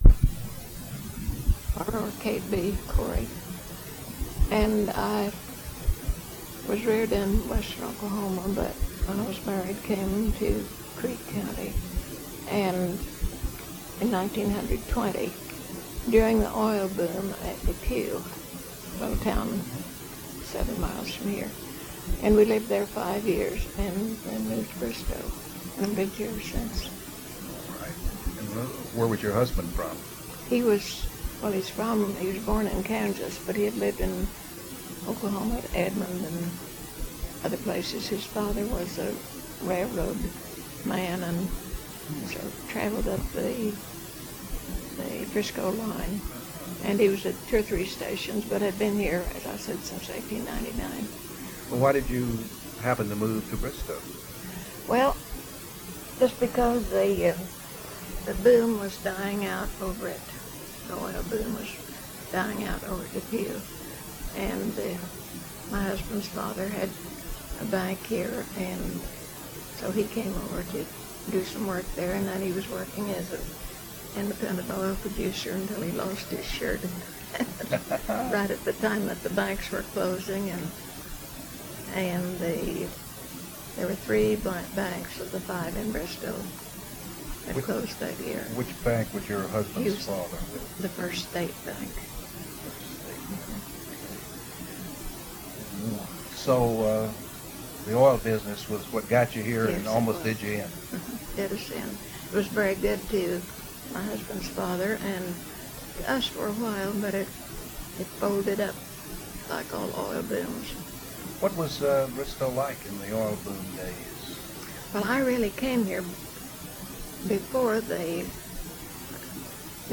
She describes life during the oil boom, teaching during racial integration, entertainment, and the effects of World War I and World War II. Preface: The following oral history testimony is the result of a cassette tape interview and is part of the Bristow Historical Society, Inc.'s collection of oral histories.
Bristow Historical Society - Oral History Archive